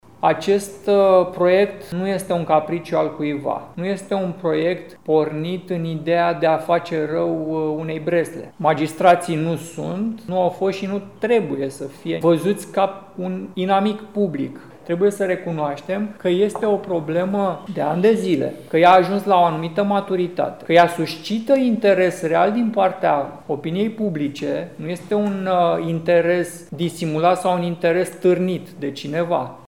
Legea care reglementează pensiile magistraților și vârsta la care aceștia pot ieși din activitate nu este îndreptată împotriva judecătorilor și procurorilor, a declarat astăzi, într-o conferință de presă, Stelian Ion, deputat USR de Constanța.